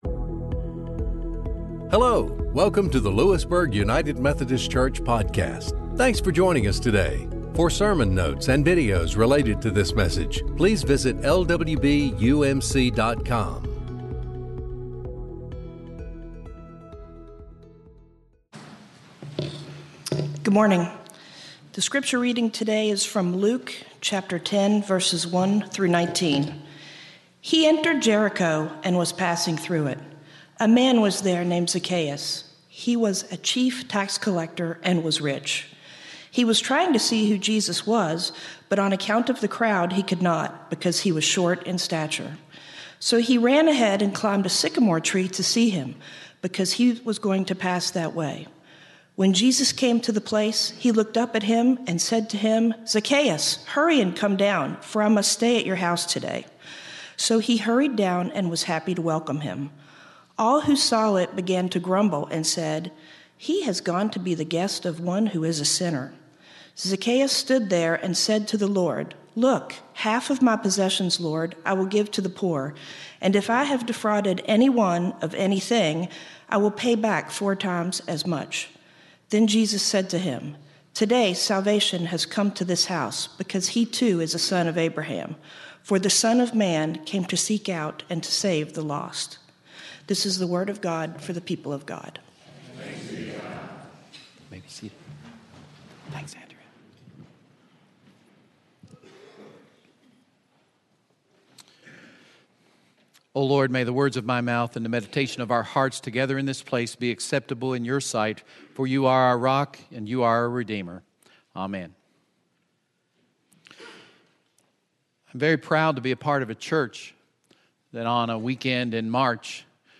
Sermon
Mbira